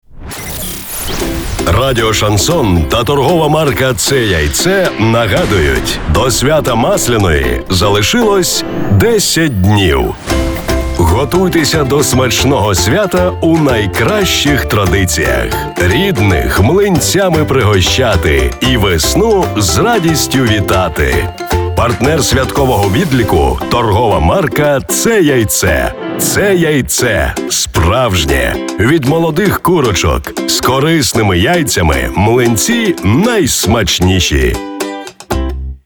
Также для запуска рекламной кампании на радио было записано 5 разных радио роликов для 5-ти радиостанций с уникальной и эмоциональной подачей, задорным и привлекательным текстом (слушайте ниже!).
Реклама на Радио Шансон — прослушать